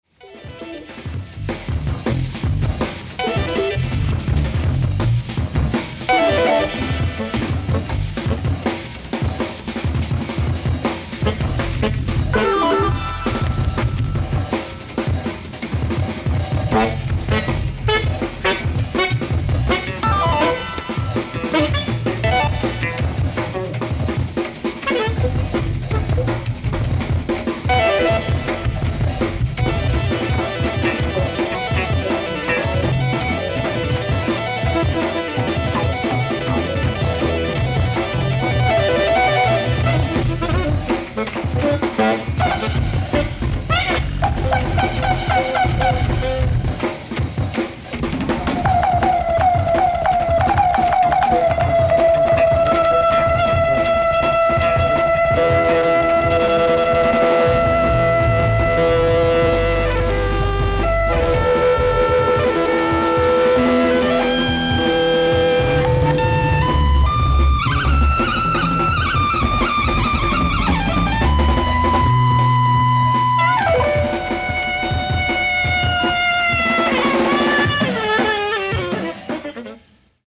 ライブ・アット・ザ・ジャバウォーキー・クラブ、シラキューズ、ニューヨーク 06/30/1973
ノイズレスにリマスター処理済音源！！
※試聴用に実際より音質を落としています。